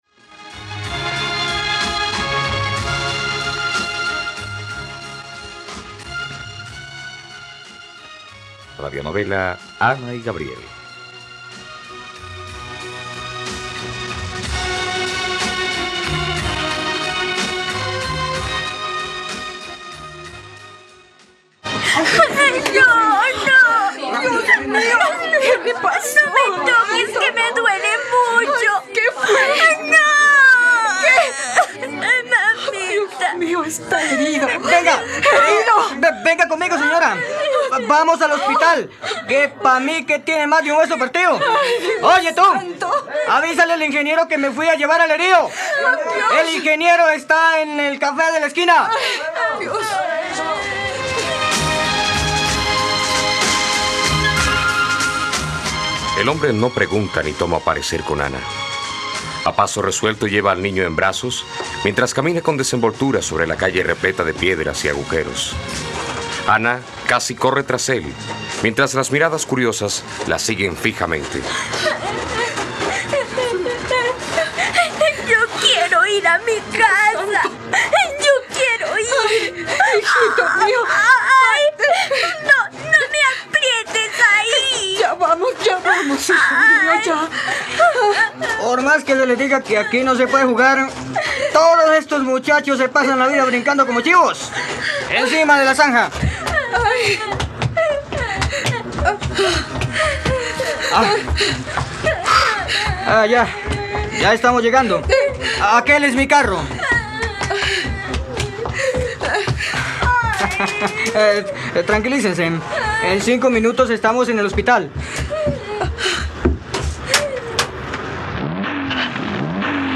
Ana y Gabriel - Radionovela, capítulo 7 | RTVCPlay